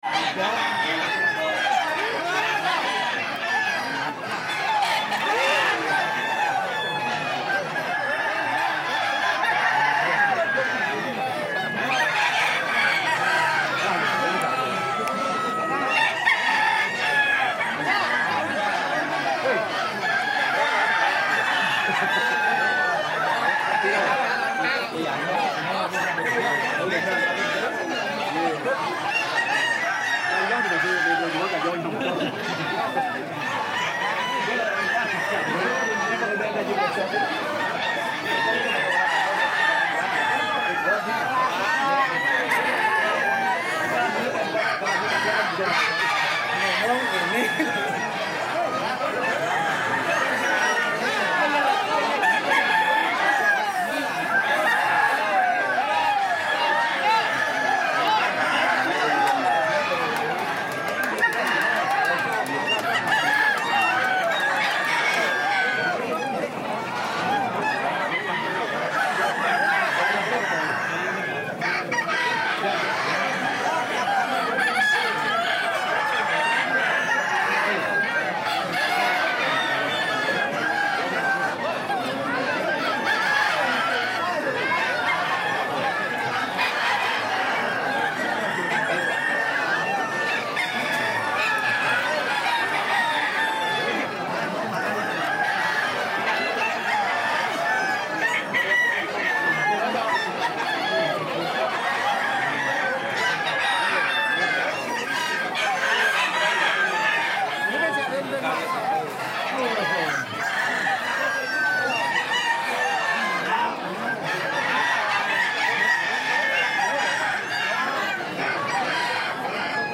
دانلود صدای بازار مرغ و خروس فروشی برای تدوین و ساخت فیلم از ساعد نیوز با لینک مستقیم و کیفیت بالا
جلوه های صوتی